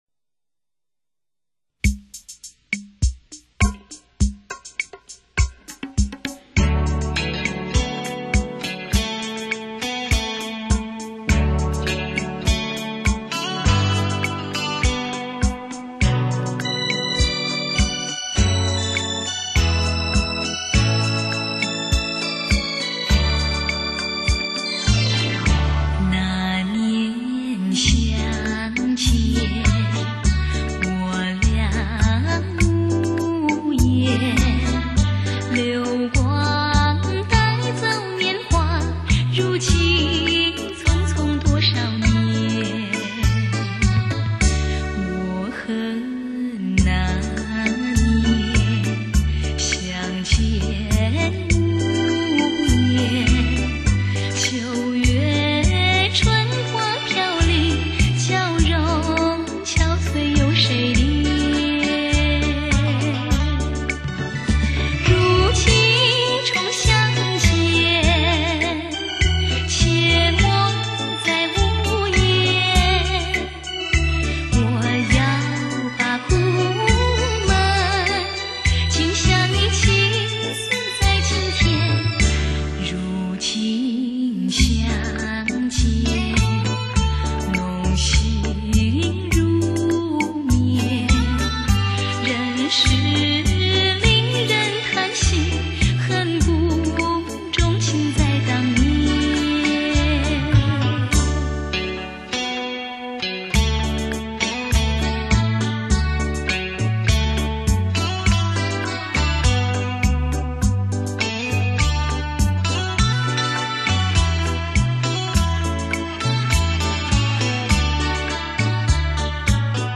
空灵，飘渺，悠扬的情歌声弥漫每一个人的心中。